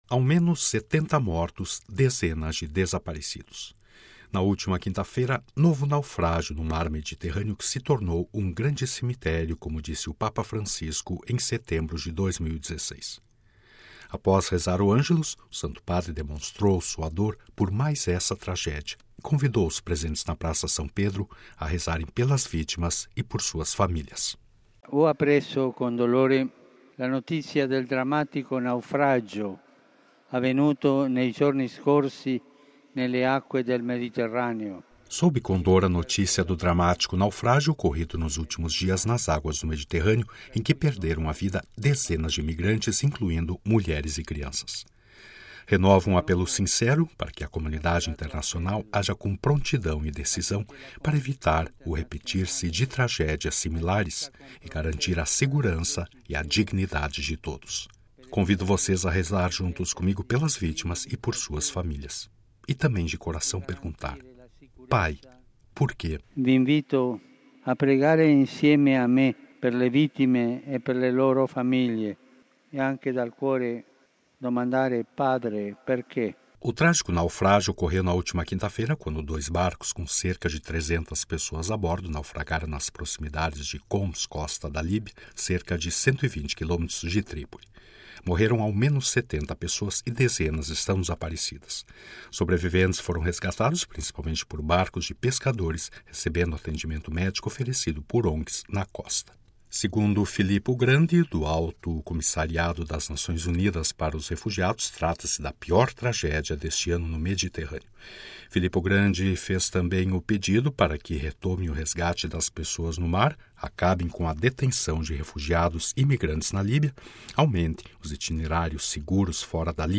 Após rezar o Angelus, o Santo Padre demonstrou sua dor por mais esta tragédia e convidou os presentes na Praça São Pedro a rezarem “pelas vítimas e por suas famílias”: